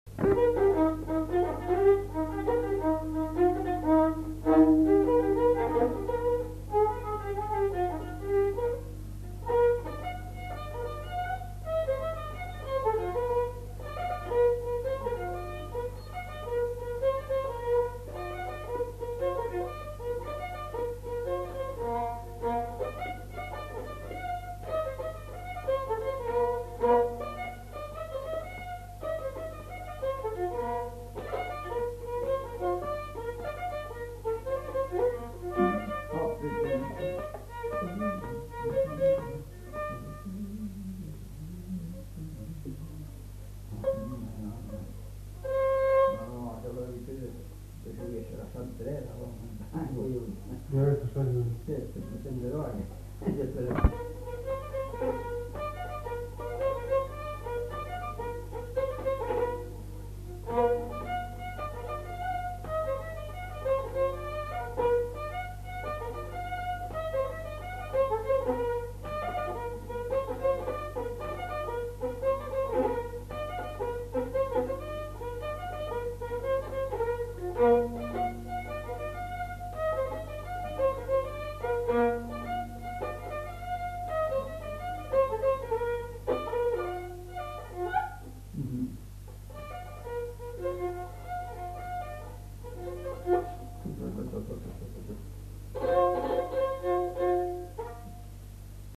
Rondeau
Lieu : Saint-Michel-de-Castelnau
Genre : morceau instrumental
Instrument de musique : violon
Danse : rondeau
Notes consultables : 2 violons.